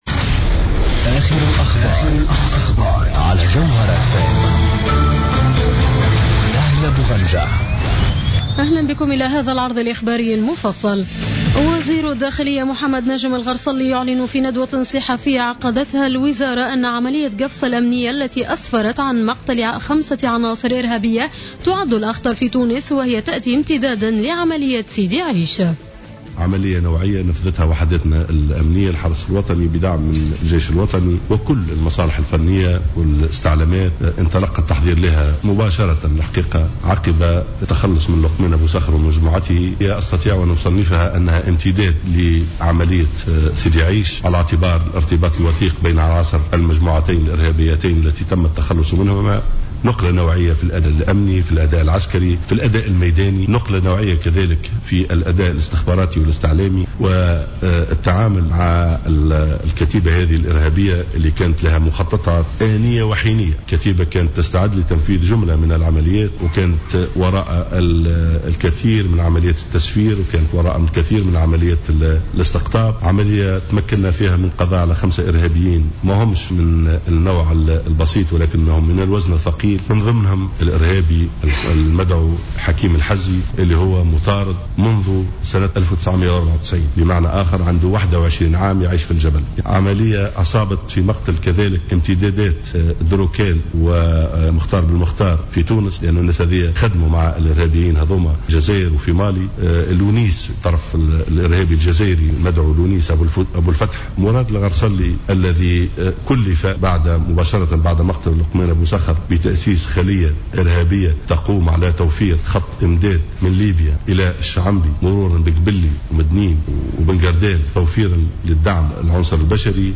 نشرة أخبار منتصف الليل ليوم الاثنين 13 جويلية 2015